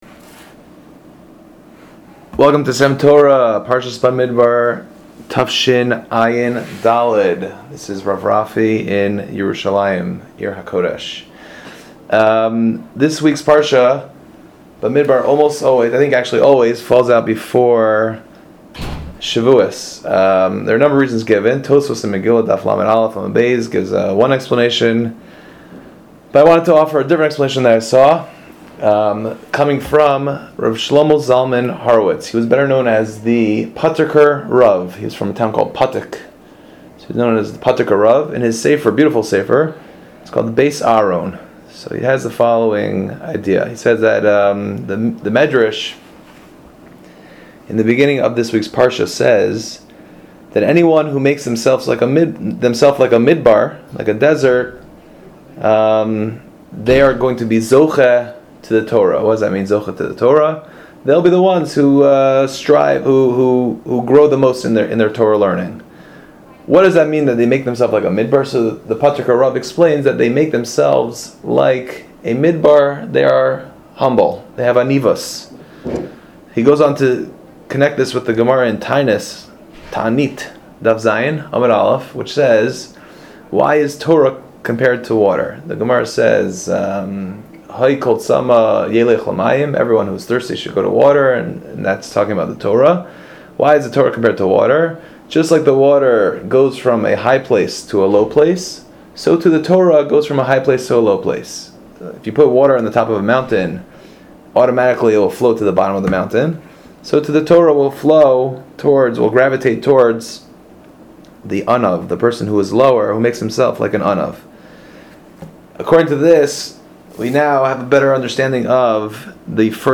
S.E.M. Torah is a series of brief divrei Torah delivered by various members of the faculty of Sha’alvim for Women.